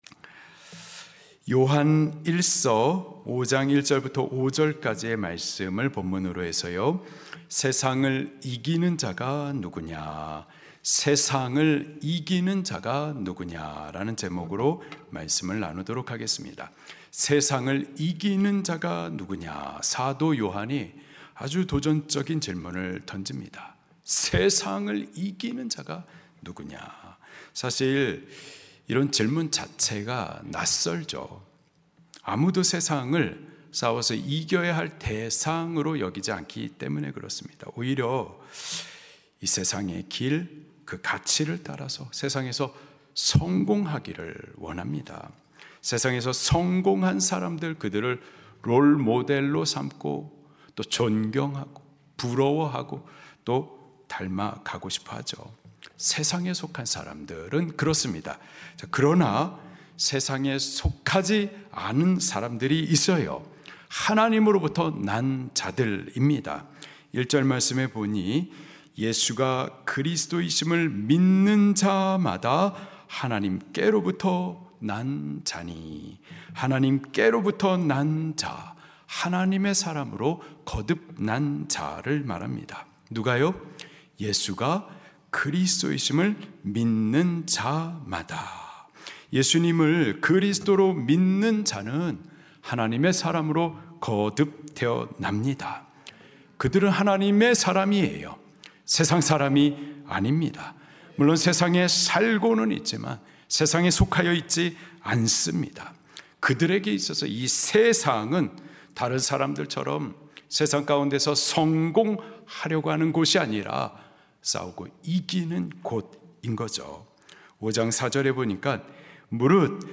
2025-12-28 설교 MP3 다운로드 세상을 이기는 자가 누구냐?